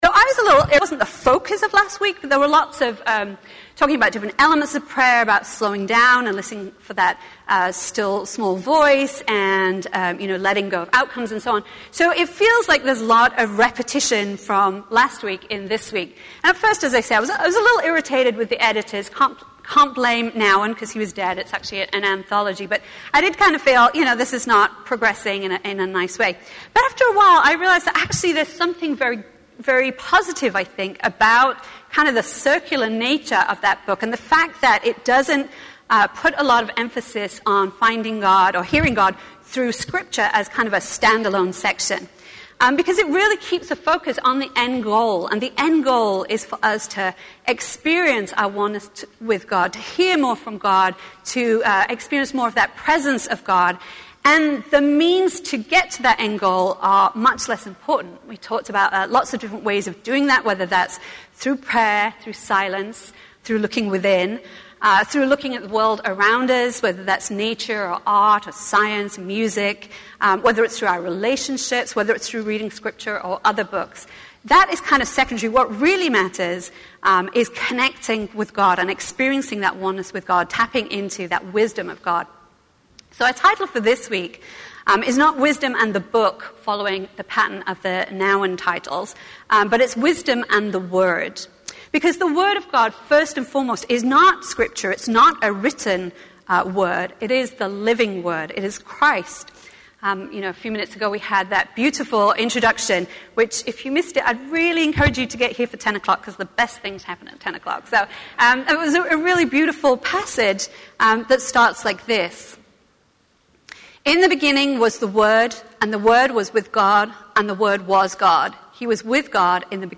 A message from the series "Finding Our Way."